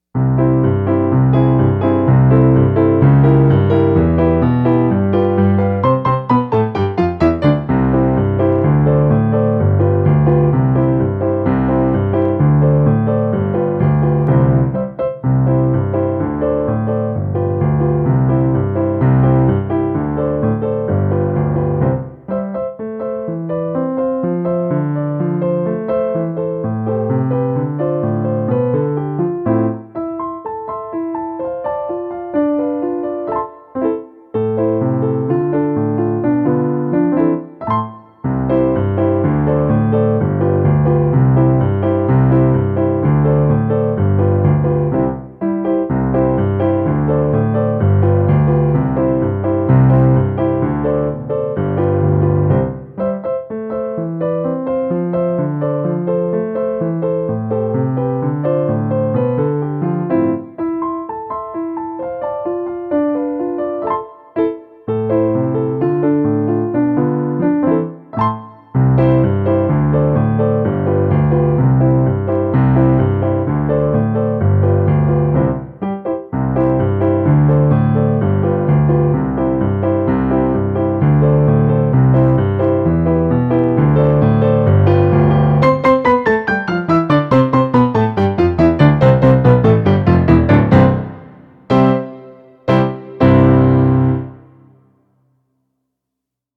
Piano s/ Clique
15-JA-VIVEU-JA-SE-LEVANTOU-COM-METRO-PIANO.mp3